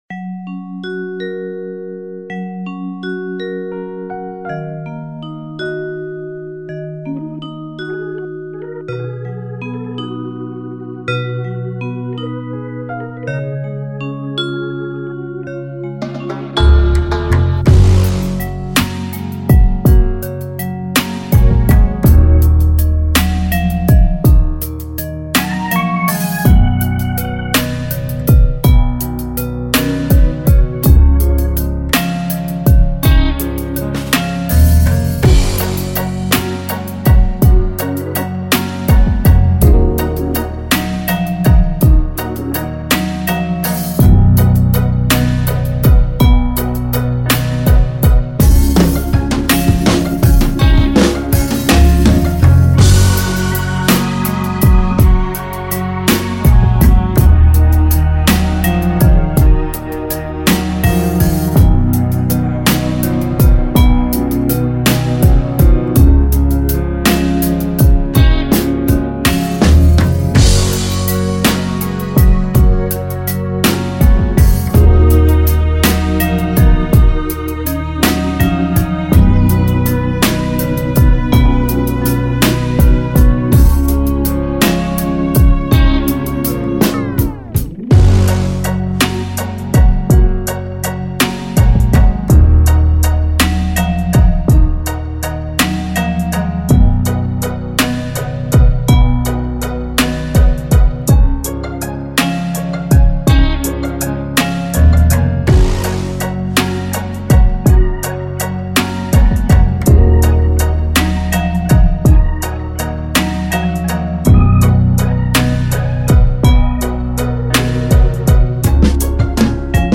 official instrumental
2024 in Dancehall/Afrobeats Instrumentals